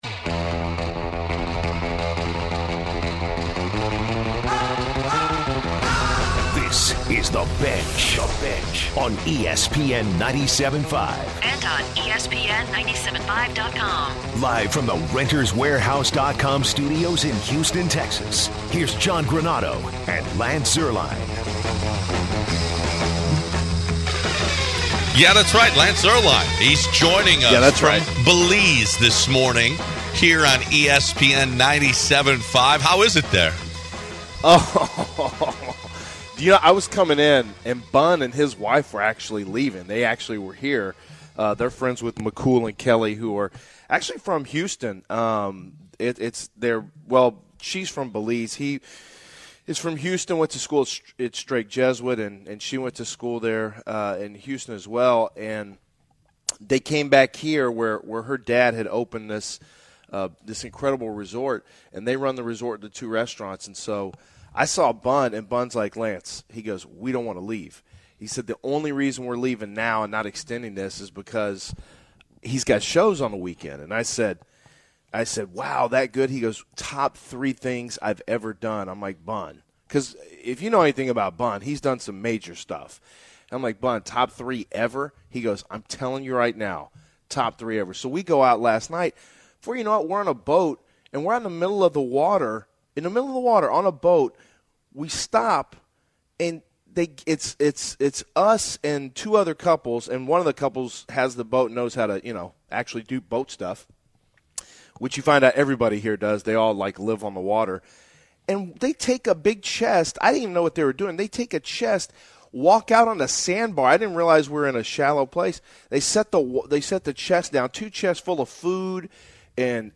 Bun B calls into the show.